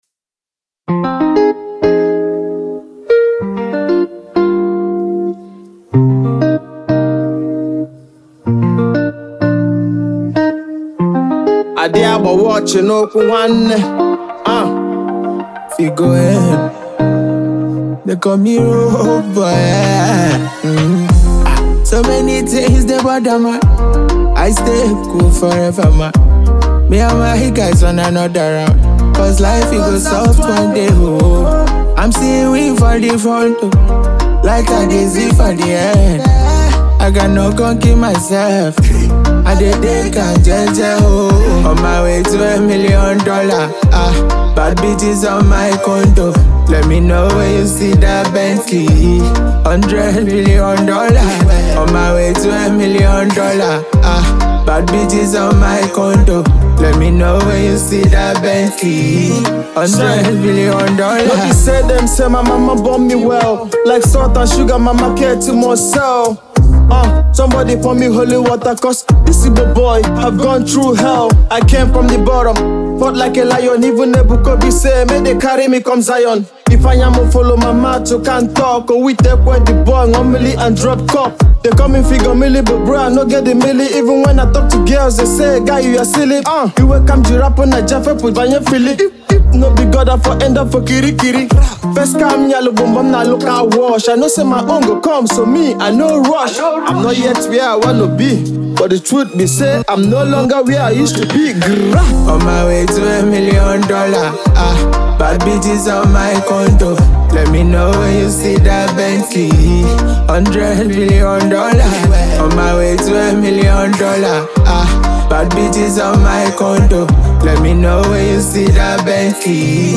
raw voice